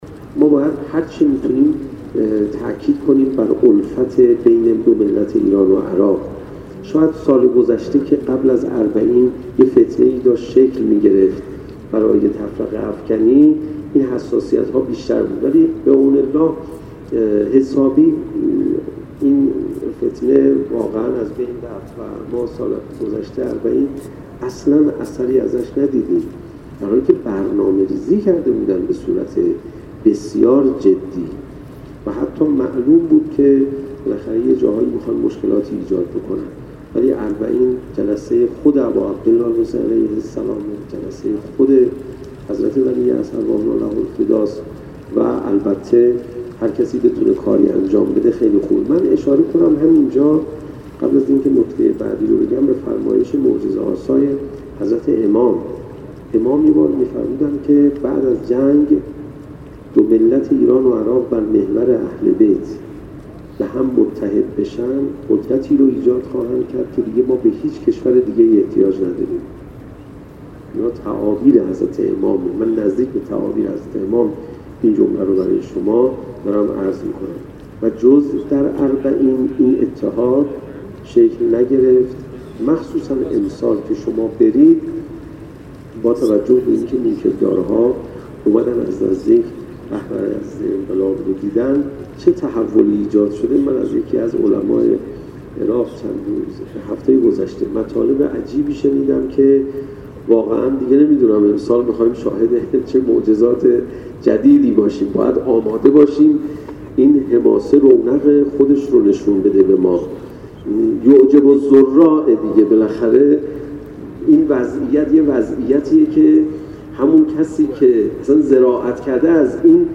به گزارش خبرنگار خبرگزاری رسا، حجت‌الاسلام والمسلمین علیرضا پناهیان استاد حوزه و دانشگاه در همایش " نقش روحانیت در حماسه اربعین حسینی" در مدرسه فیضیه قم بابیان اهمیت حضور جوانان در راهپیمایی اربعین بیان داشت: در فرهنگ‌سازی حضور در راهپیمایی اربعین حسینی روحانیت وظیفه‌ای سنگین بر عهده دارد.